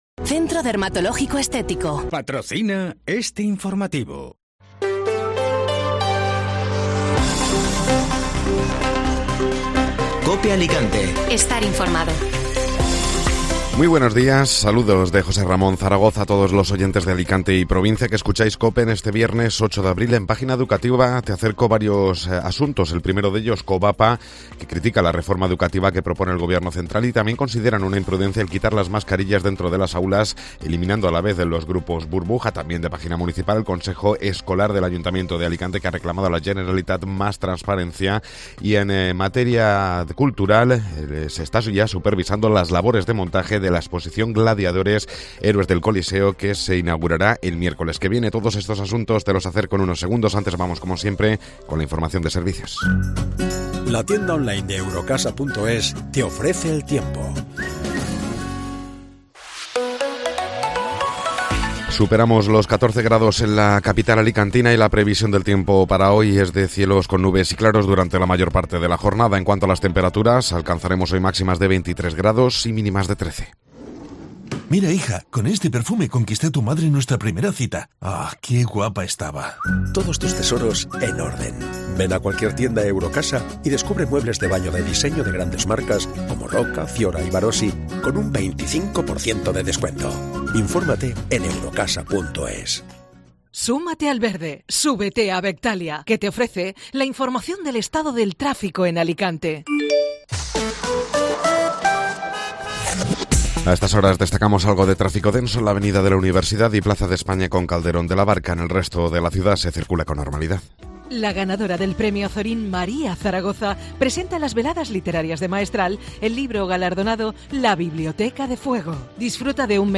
Informativo Matinal (Viernes 8 de Abril)